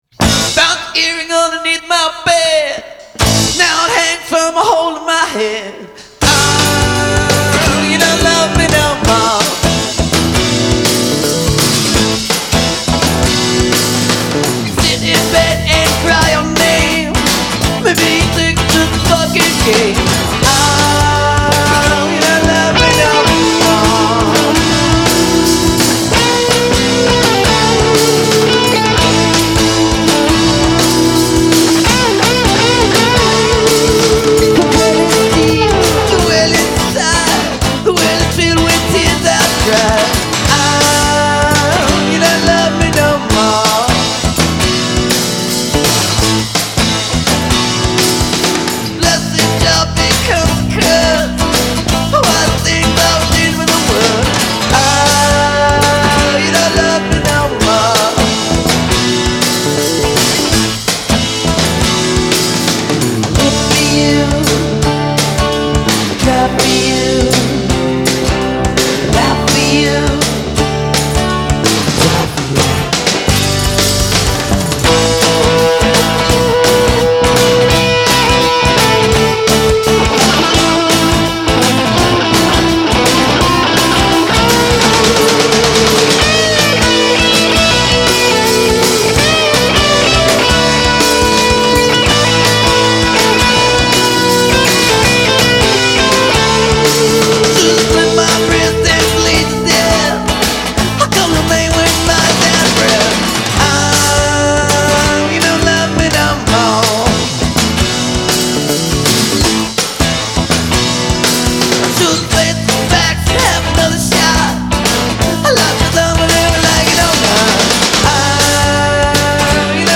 Late Eighties indie pop from Boston